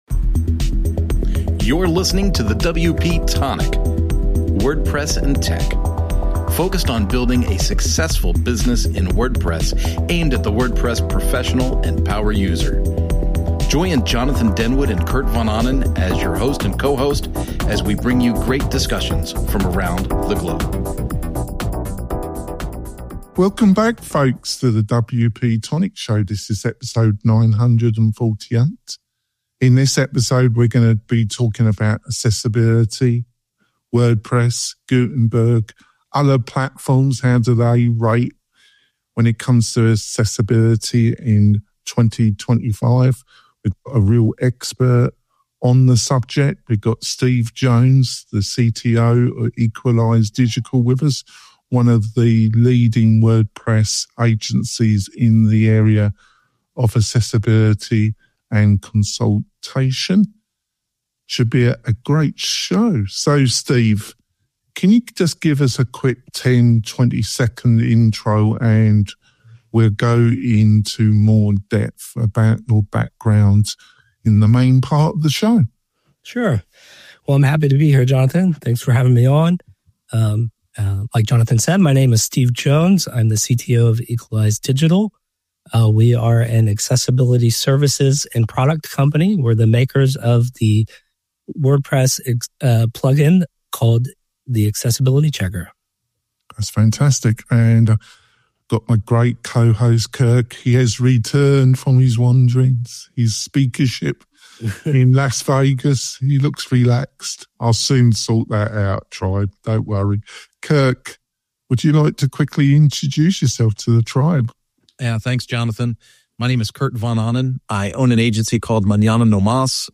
We interview creative WordPress and startup entrepreneurs, plus online experts who share insights to help you build your online business.